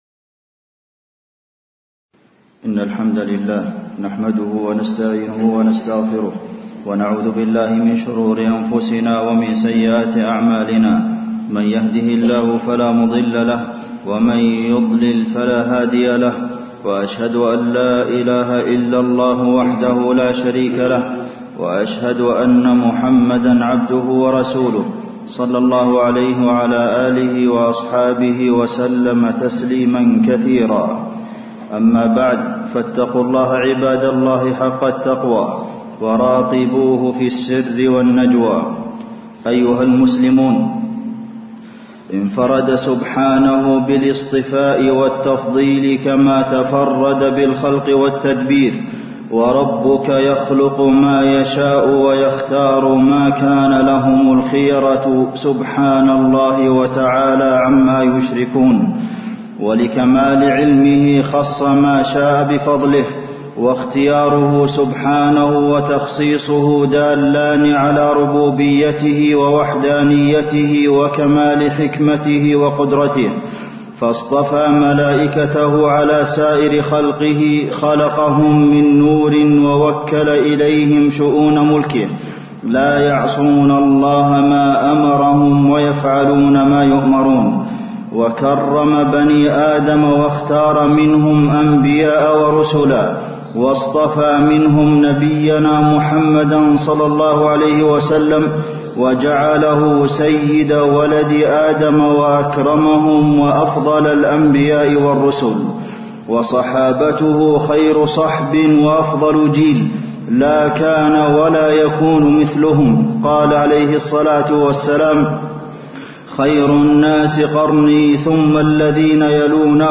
تاريخ النشر ١ رجب ١٤٣٧ هـ المكان: المسجد النبوي الشيخ: فضيلة الشيخ د. عبدالمحسن بن محمد القاسم فضيلة الشيخ د. عبدالمحسن بن محمد القاسم خير الأعمال وأفضلها